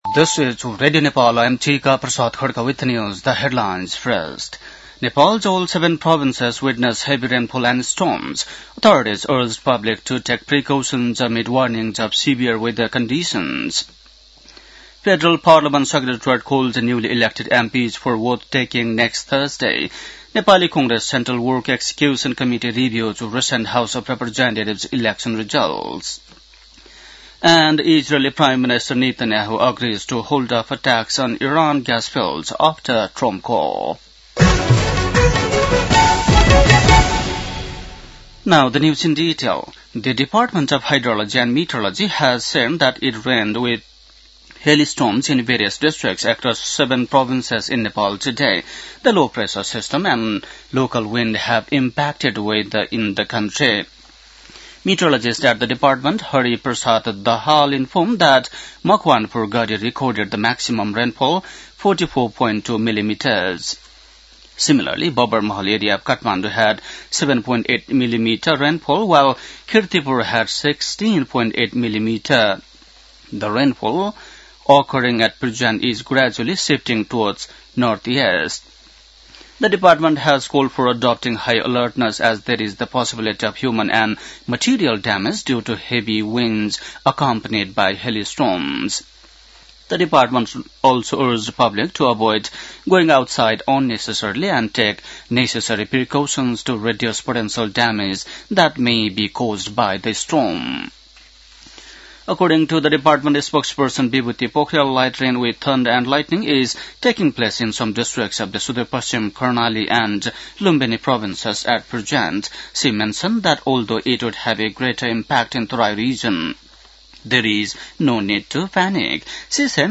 बेलुकी ८ बजेको अङ्ग्रेजी समाचार : ६ चैत , २०८२
8-pm-english-news-12-06.mp3